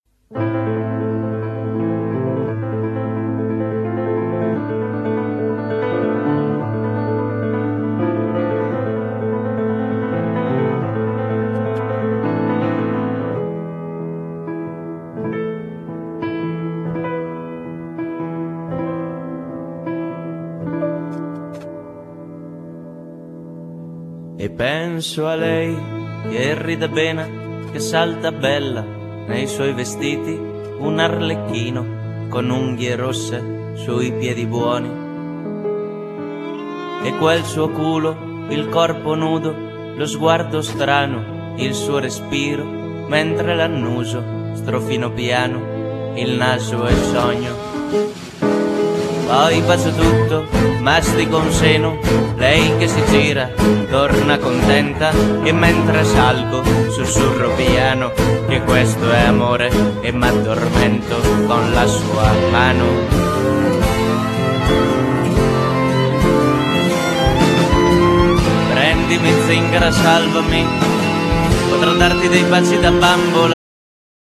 Genere : Pop